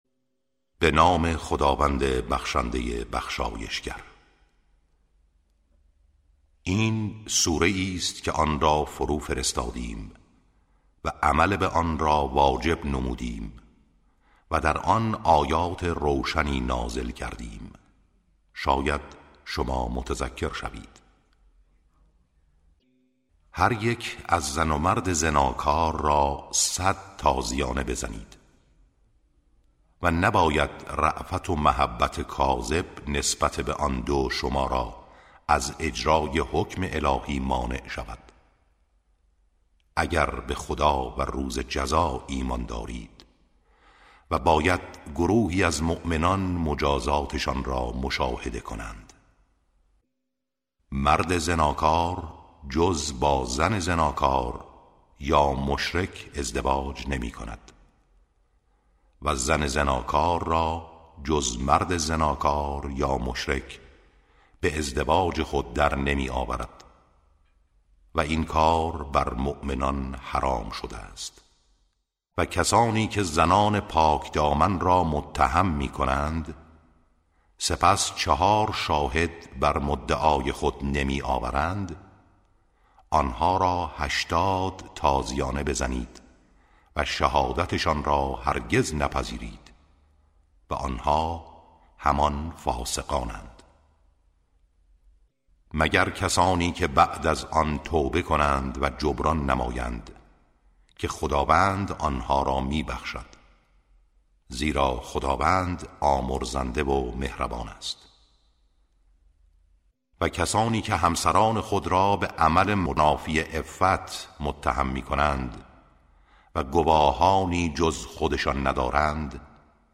ترتیل صفحه ۳۵۰ سوره مبارکه نور(جزء هجدهم)